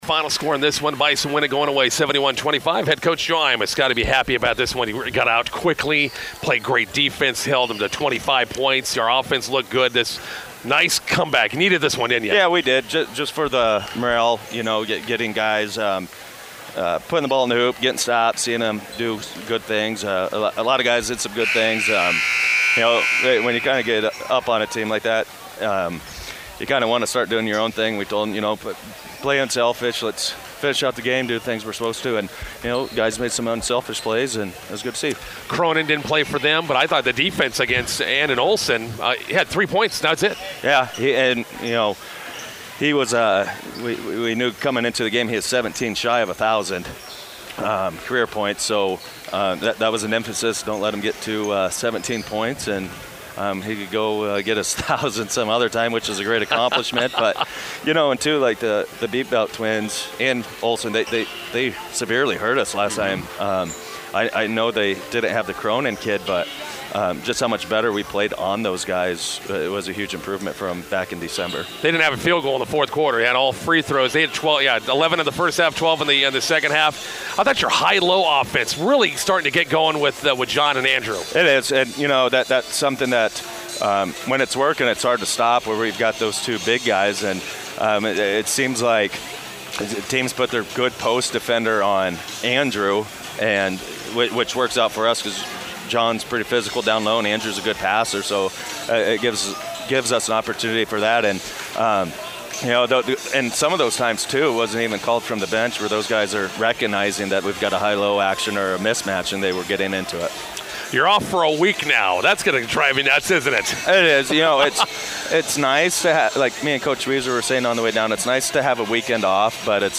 INTERVIEW: Bison boys win consolation game vs. Valentine, prepare for Class C1 top-ranked Ogallala next week.